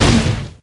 rhinostep_01.ogg